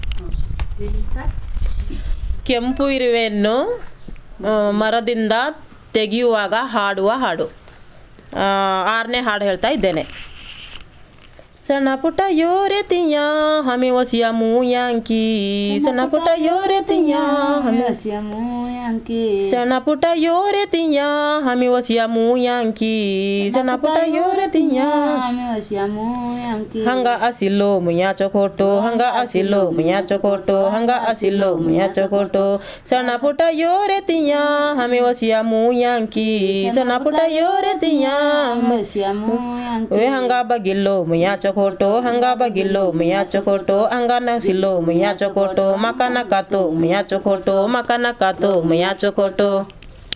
traditional folk song
while catching red ants from a tree.